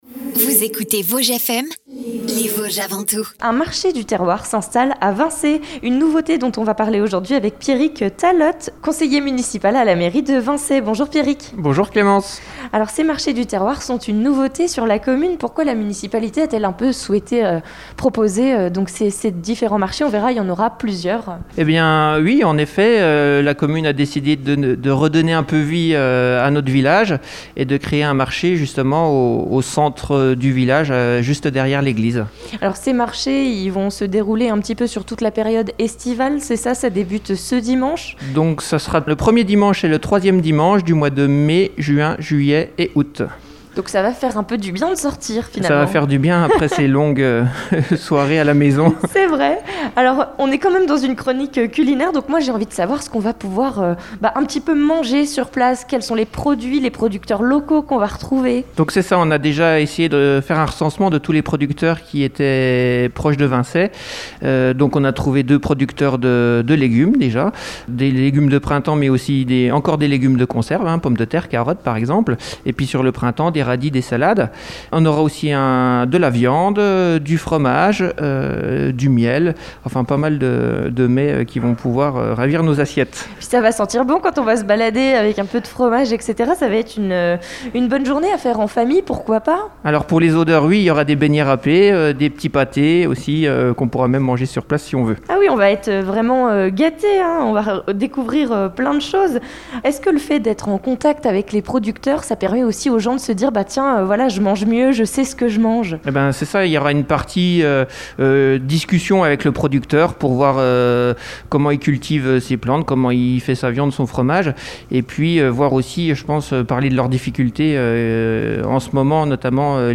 Petit reportage audio Vosges FM à écouter : https